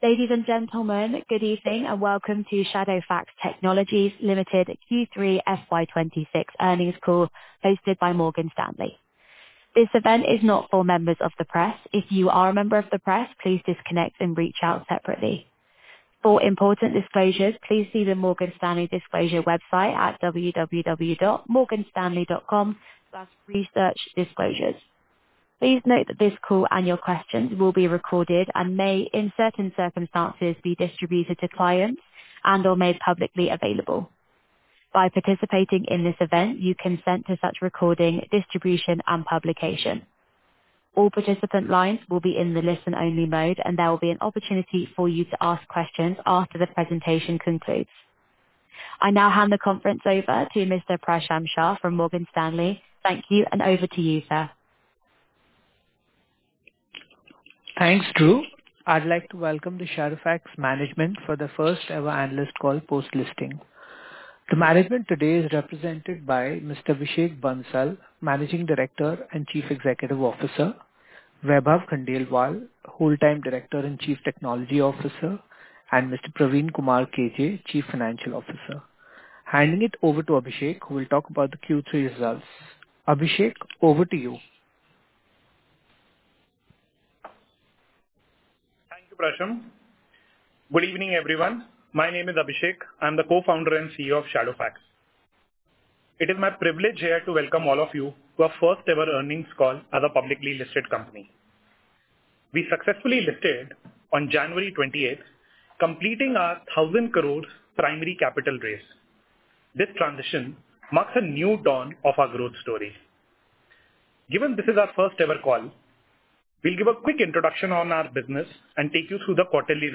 Concalls
Shadowfax+Technologies+Ltd+Q3+FY26+Earnings+Conference+Call.mp3